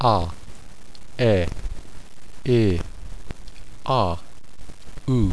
Vowels
a as in father   a as in father
e as in bed   h as in they